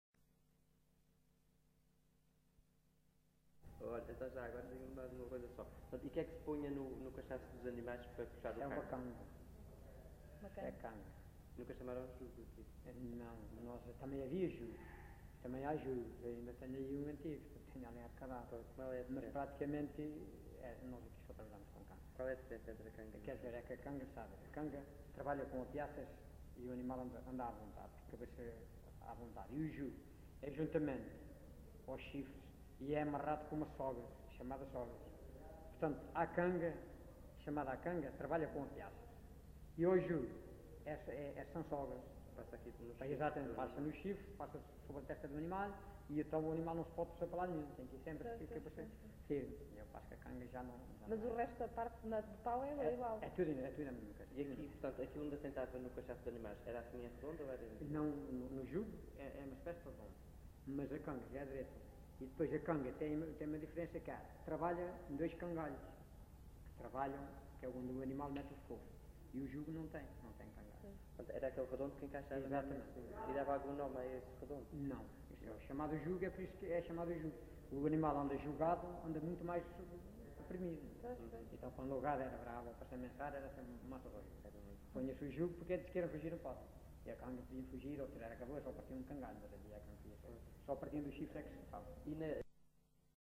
LocalidadeMonsanto (Idanha-a-Nova, Castelo Branco)